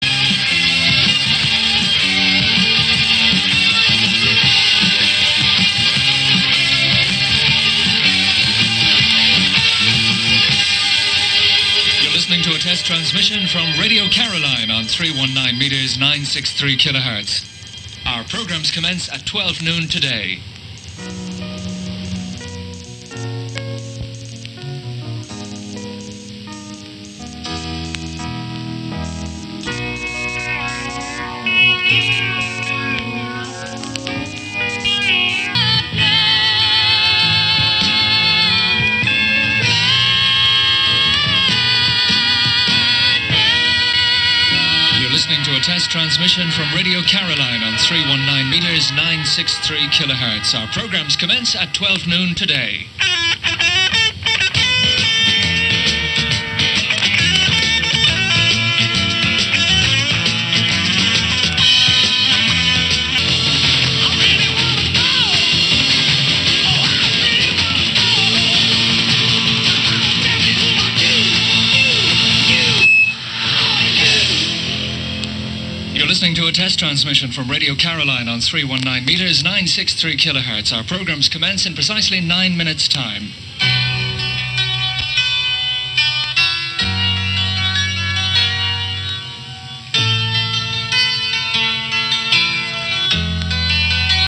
At half past midnight on 9th August 1983 the transmitter was switched on for about 30 minutes with a carrier signal and further carrier signals were transmitted during the following few days in preparation for the commencement of regular broadcasts.
Test transmissions for the return of Radio Caroline, August 1983
caroline test transmissions 20 Aug 1983.mp3